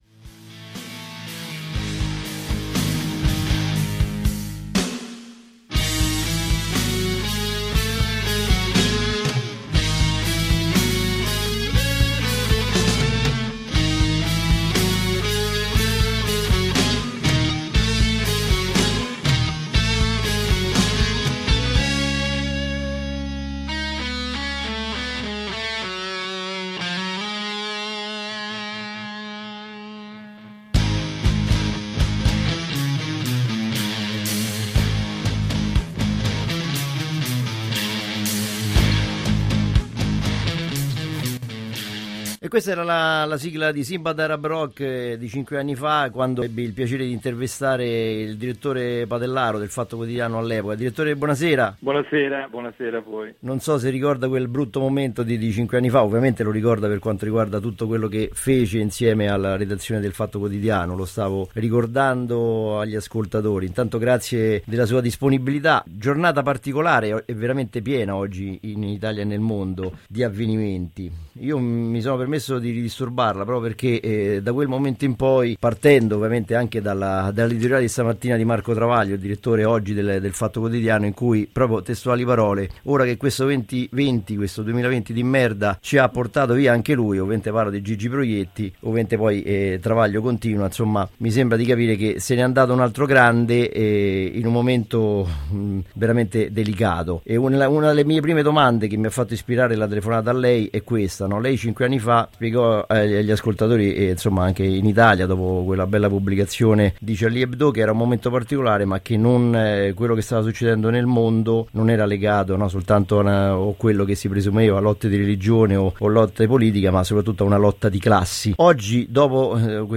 Intervista ad Antonio Padellaro